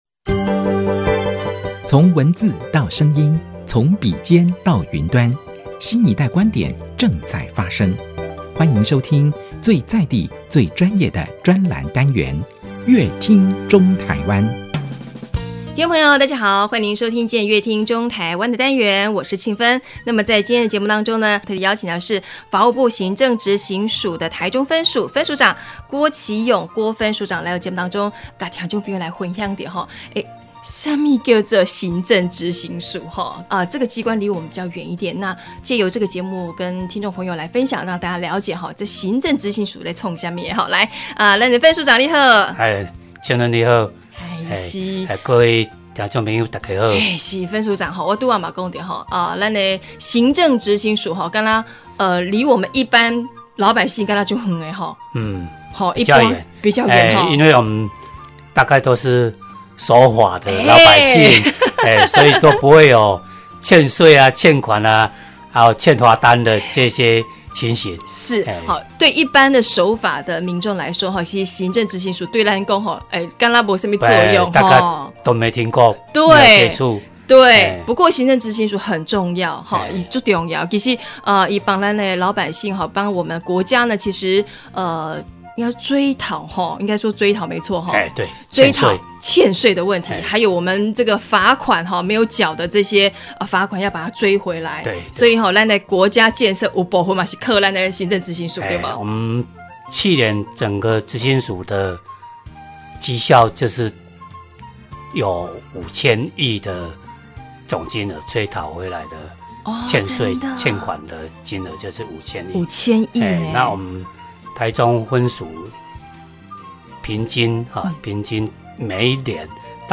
本集來賓：法務部行政執行署臺中分署郭棋湧分署長 本集主題：「兼顧情理法 為民服務」 本集內容： 對一般守法民眾